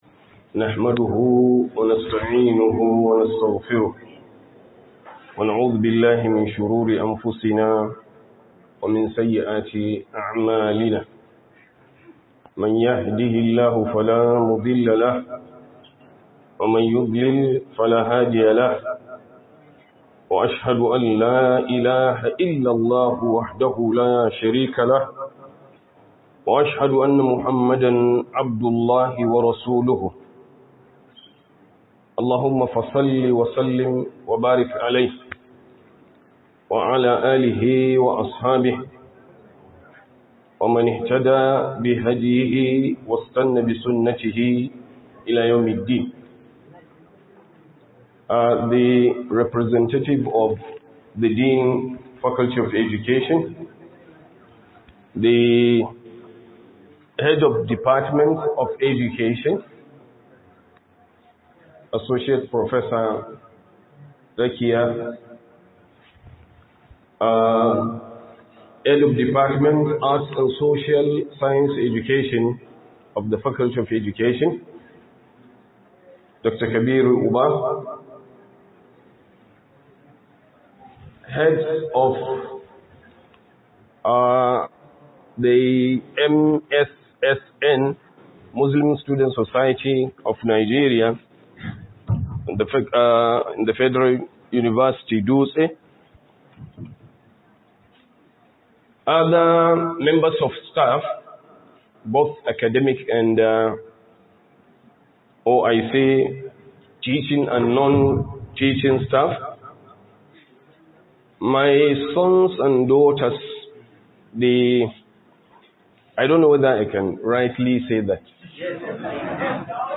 Harmonizing Academic Excellence with Islamic Commitments; A Call to Purposeful Student Life - Muhadara